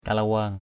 /ka-la-wa:ŋ/ (d.) cây dầu rái.